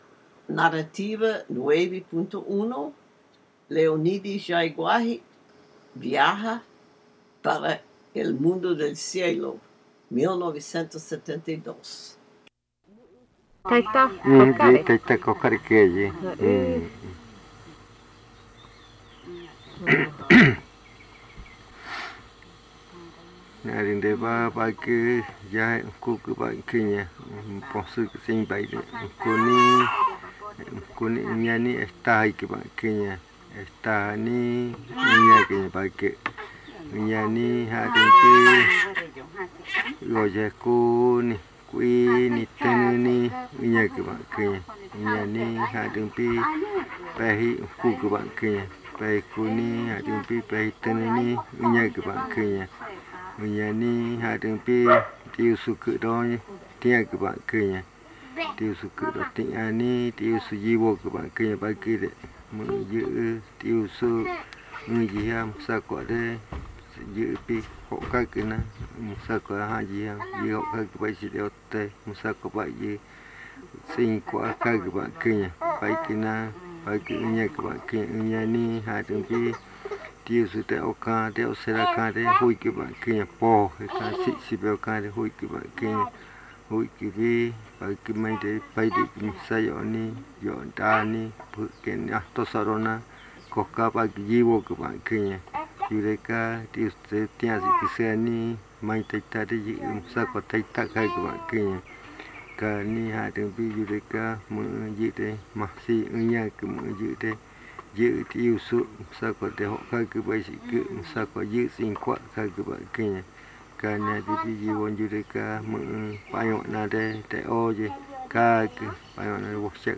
Buenavista, río Putumayo (Colombia)